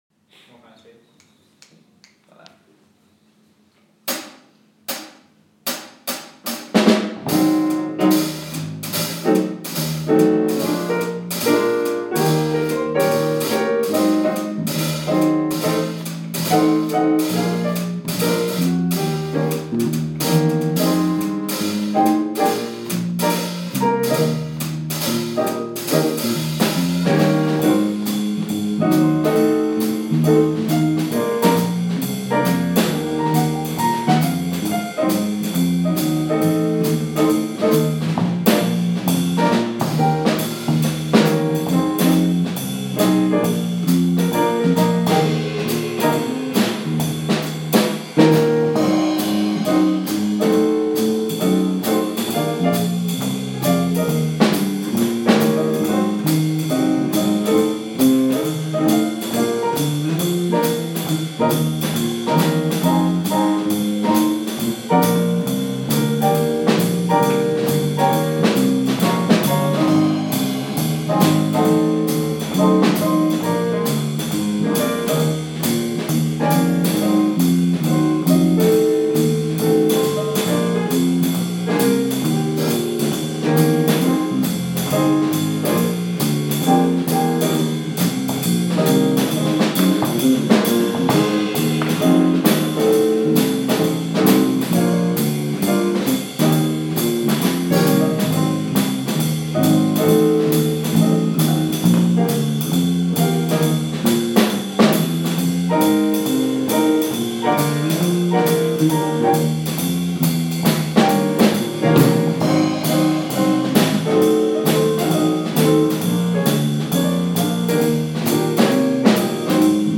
funk dumpling backing